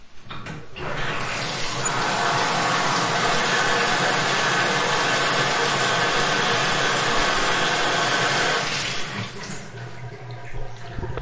听起来像鼓声" 模拟拍手
描述：一个由棍子撞在一起触发的拍子，在逻辑上编辑了一下一个sm 58被用来触发
标签： 击掌 单击 合成器 水龙头 触发
声道立体声